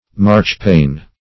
Marchpane \March"pane`\, n. [Cf. It. marzapane,Sp. pan,.